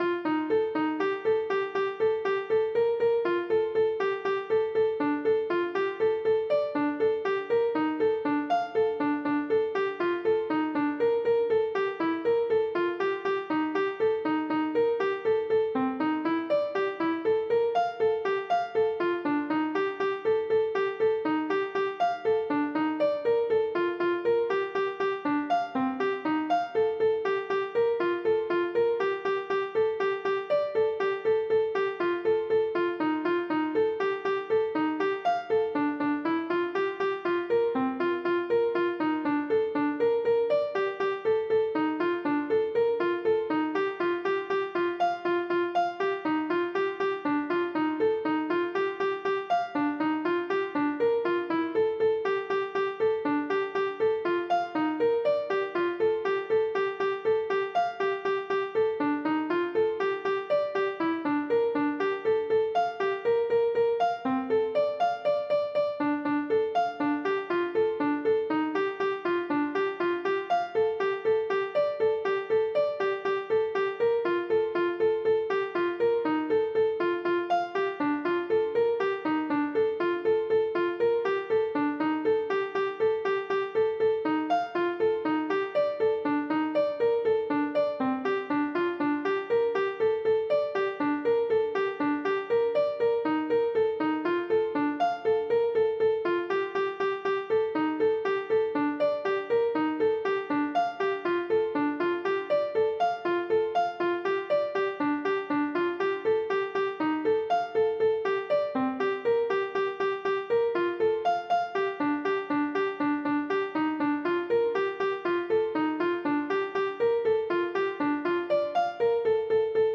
« Protéodie » est un mot formé de « protéine » et de « mélodie » car ce sont des airs de musique (des mélodies) qui agissent sur la fabrication de protéines dans votre corps.